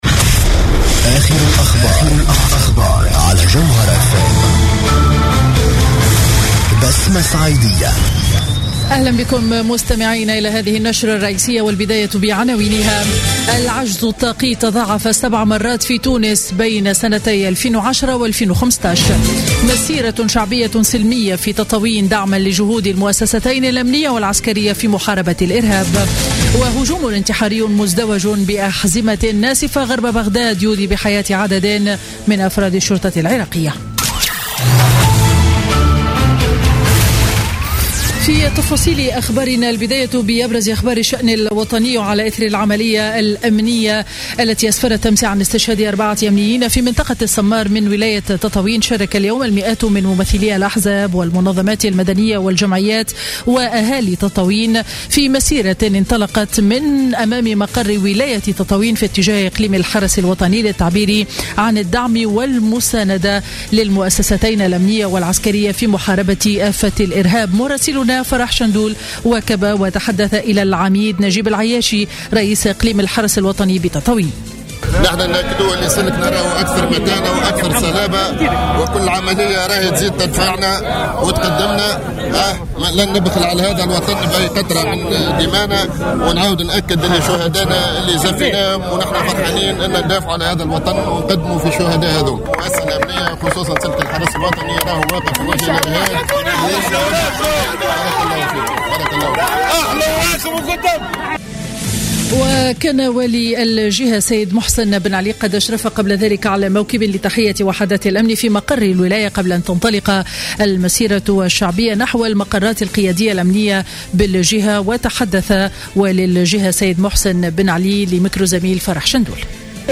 نشرة أخبار منتصف النهار ليوم الخميس 12 ماي 2016